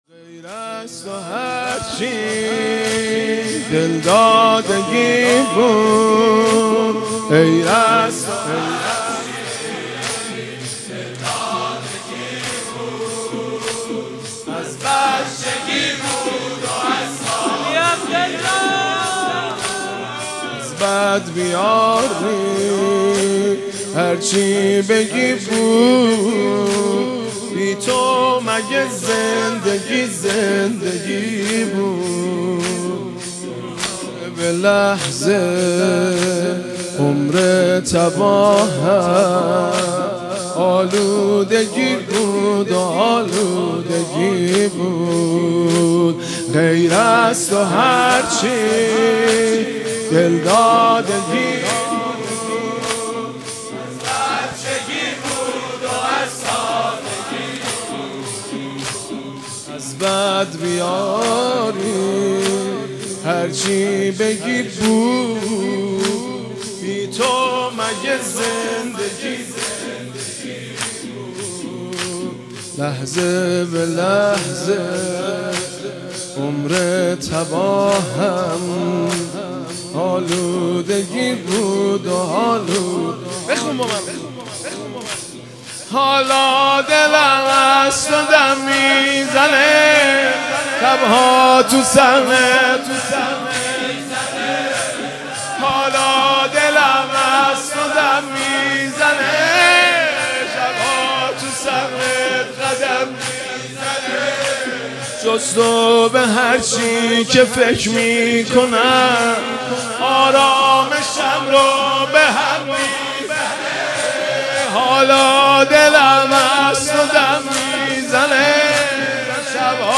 مناجات خوانی و مداحی حاج مهدی رسولی در شب بیست و هفتم ماه رمضان
دانلود مناجات خوانی و مداحی زیبا و دلنشین از حاج مهدی رسولی در شب بیست و هفتم ماه رمضان